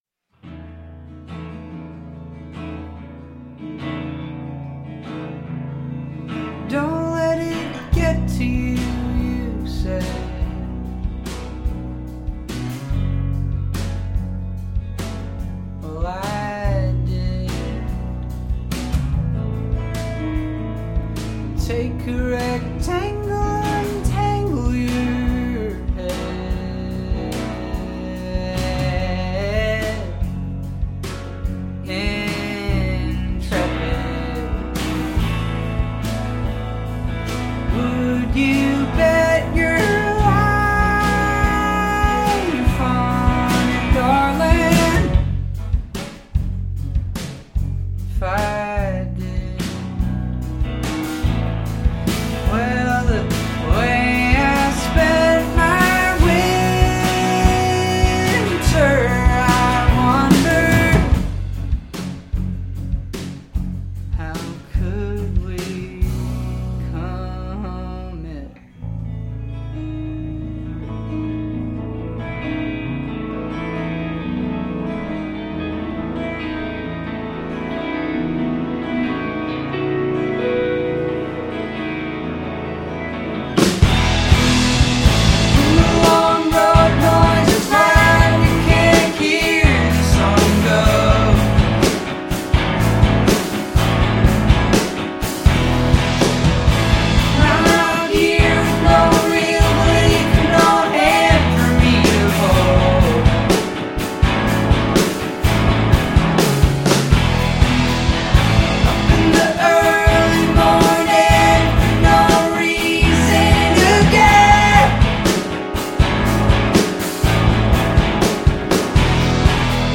Its a beautiful & slowly building release